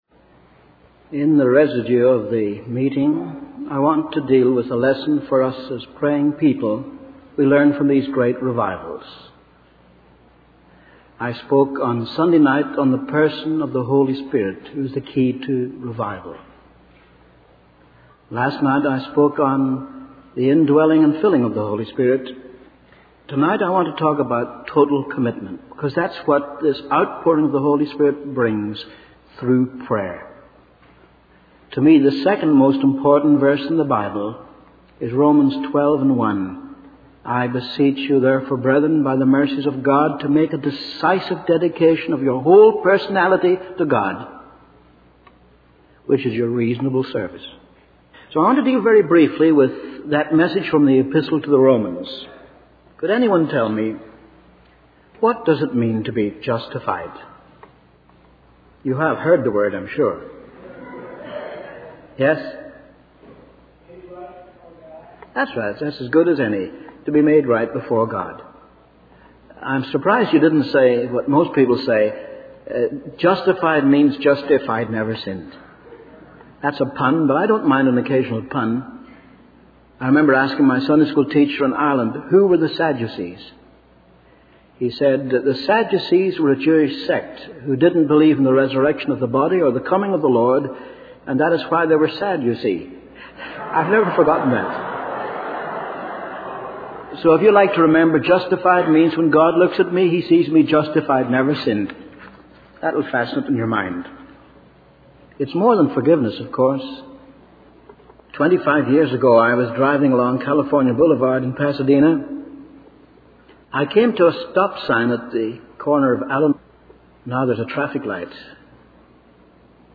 In this sermon, the speaker focuses on the subject of sin and its impact on humanity. He explains that both Gentiles and Jews are sinners, emphasizing that all people have sinned and fallen short of God's glory. The speaker then moves on to discuss the concept of justification, highlighting that through faith in Jesus Christ, believers can be justified and have peace with God.